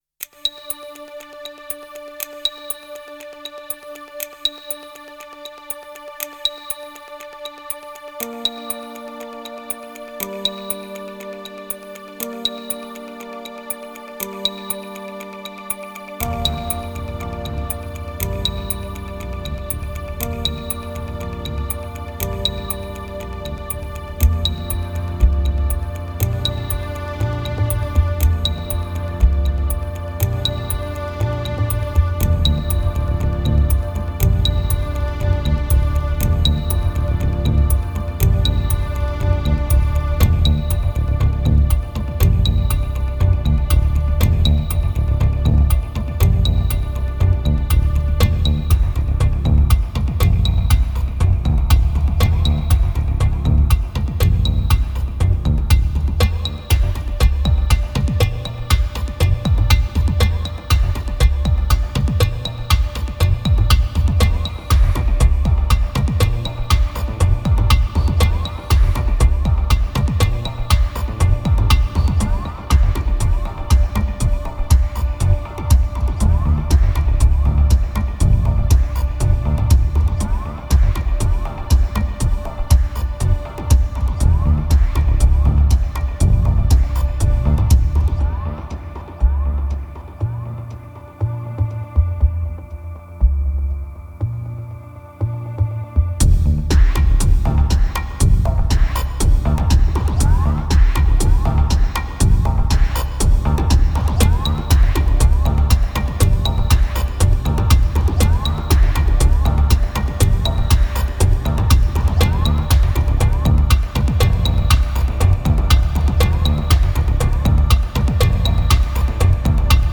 2579📈 - 57%🤔 - 120BPM🔊 - 2010-03-12📅 - 75🌟